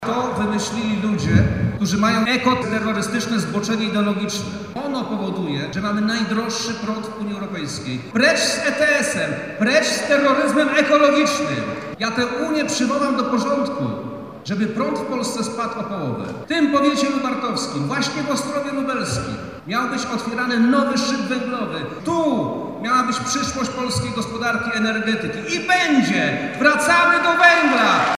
Koniec Europejskiego Systemu Handlu Emisjami (ETS) i powrót do węgla zapowiedział Przemysław Czarnek kandydat Prawa i Sprawiedliwości na przyszłego premiera podczas spotkania w Lubartowie.